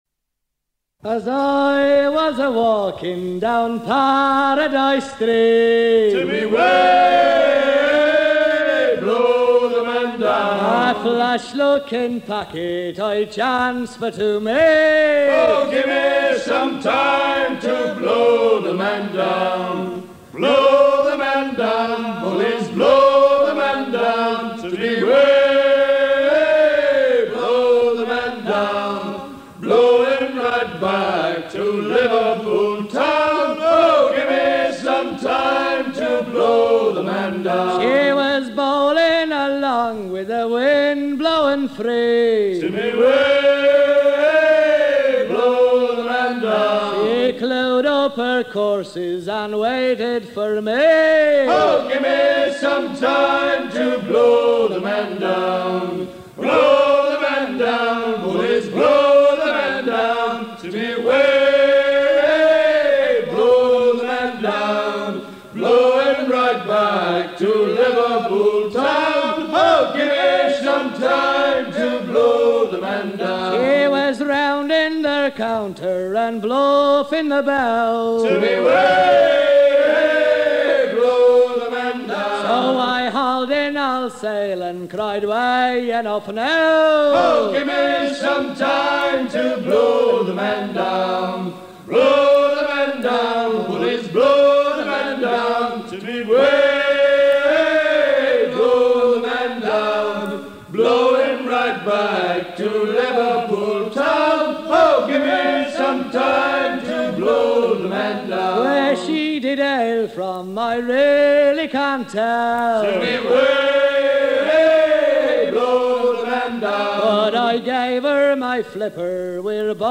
shanty qui servait à hisser le grand hunier volant sur les packets ships desservant les lignes transatlantiques
Pièce musicale éditée